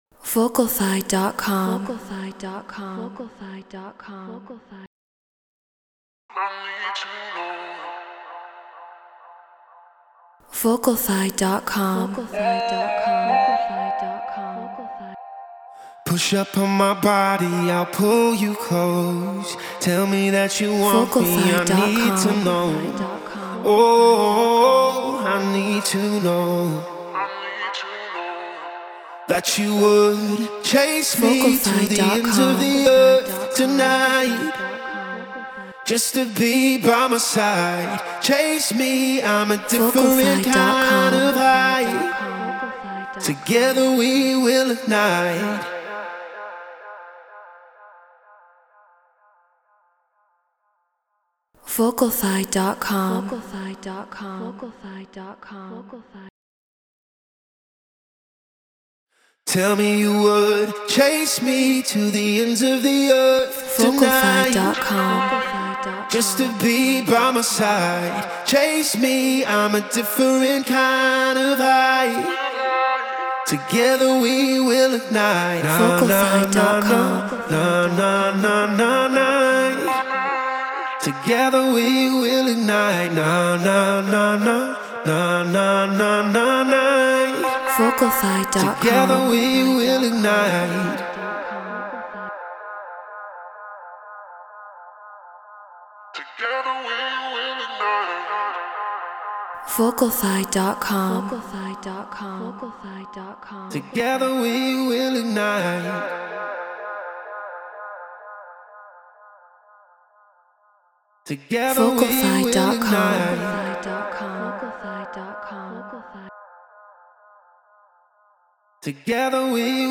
House 127 BPM Fmin
Flea 47 RME Babyface Pro FS Ableton Live Treated Room